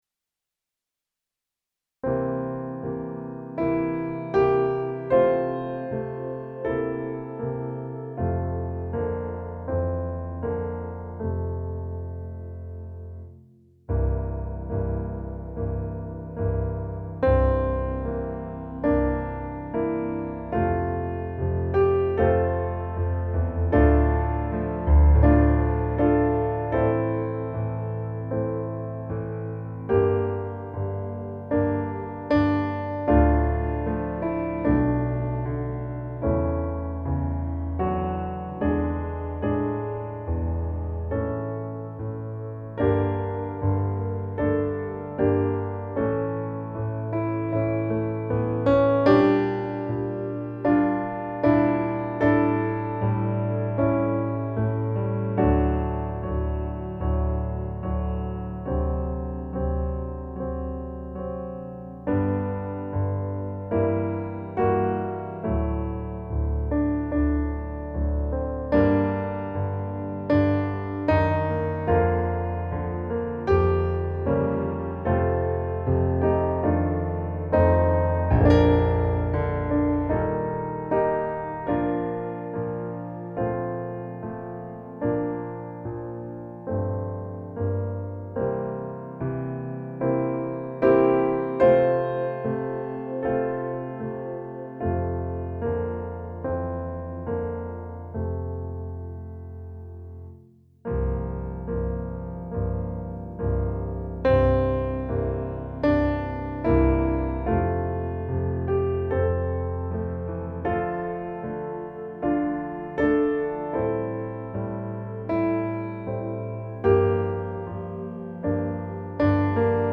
Gläns över sjö och strand - musikbakgrund
Musikbakgrund Psalm